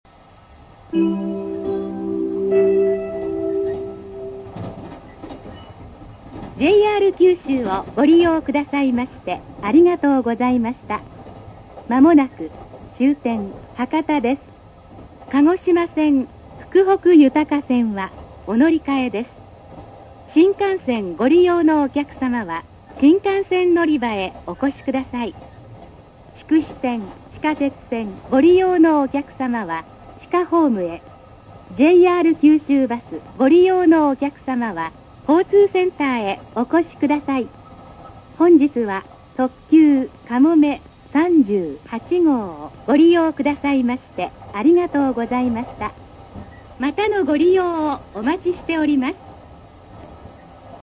博多到着前の降車放送です。
最後の謝礼部分は、やたら継ぎ足した感が聞こえます.......。